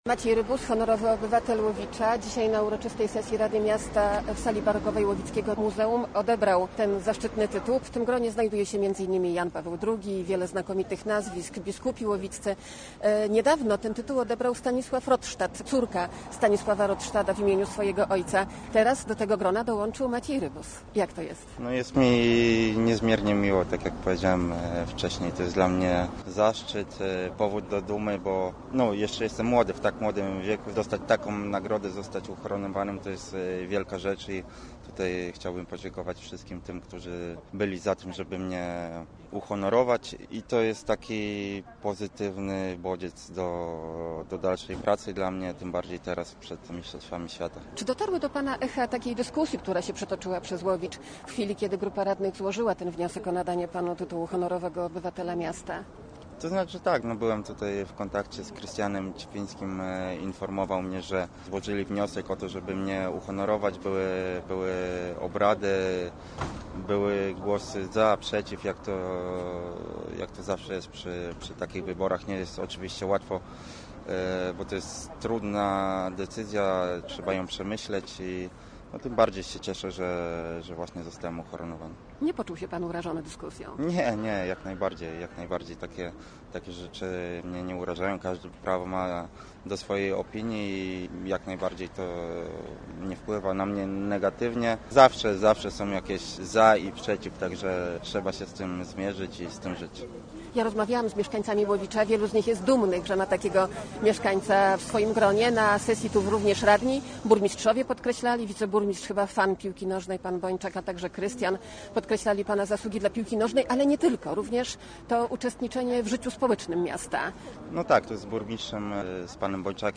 Reprezentant Polski w piłce nożnej odbierał tytuł podczas uroczystej uroczystej sesji rady miasta, która odbyła się w sali barokowej łowickiego muzeum.